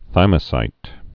(thīmə-sīt)